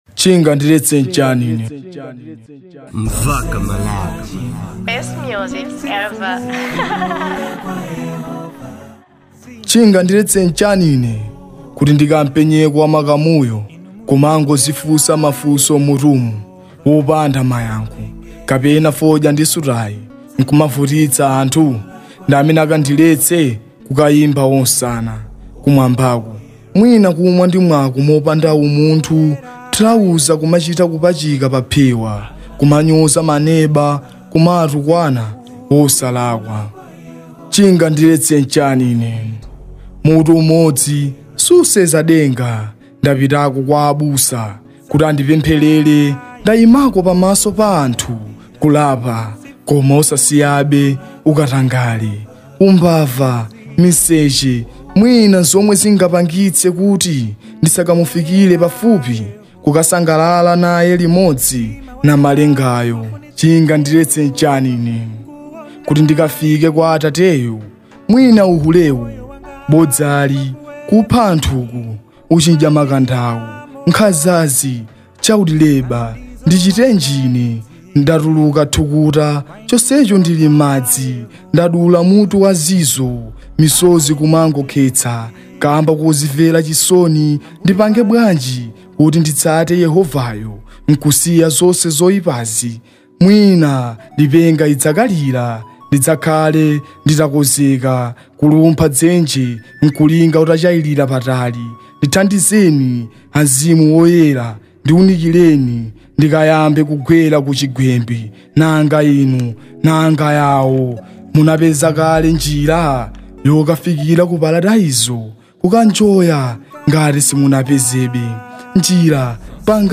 type: poem